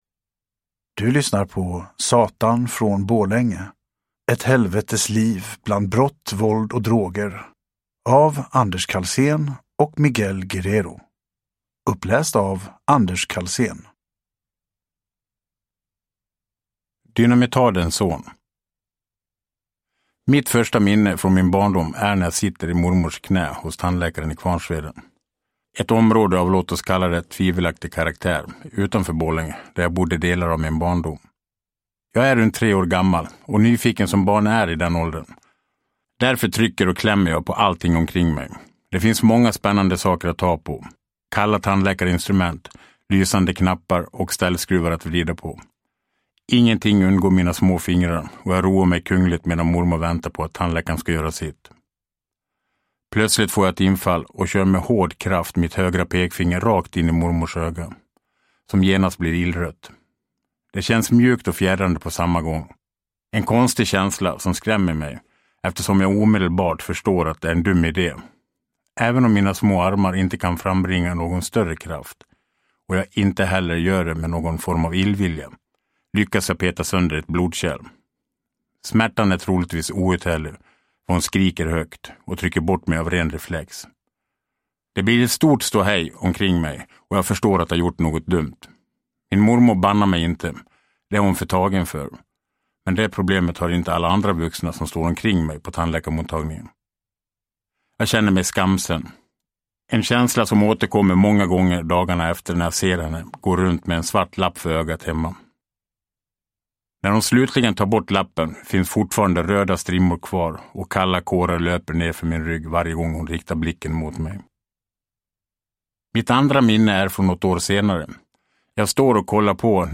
Satan från Borlänge – Ljudbok – Laddas ner